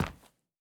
added stepping sounds
Linoleum_Mono_01.wav